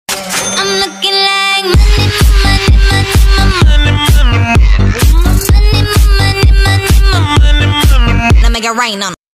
youtube-twitch-alert-sound-effect-donation-1_prSav2v.mp3